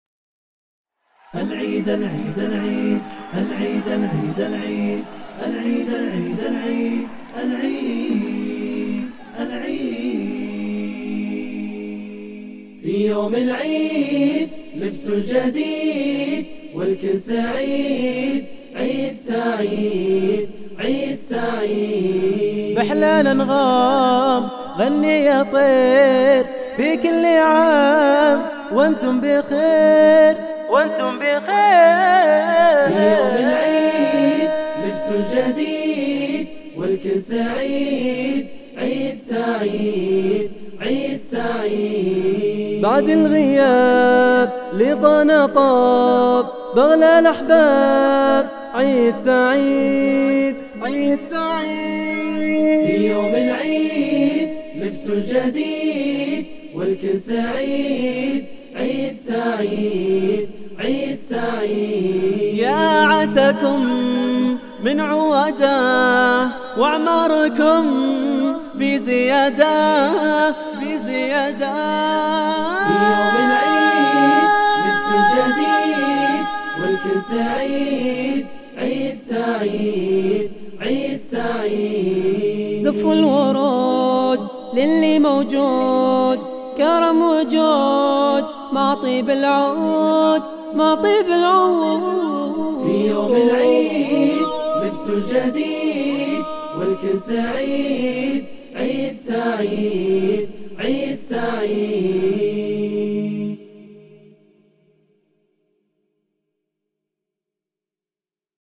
[ أنشودة متواضعة ] !
حيث سجلّت وهُندست بالأمس ولمدة 3 ساعات فقط !
الحقيقة القصيدة من حيث الآداء والإخراج رااائعة ,,
لكن التوزيع مو واضح ؟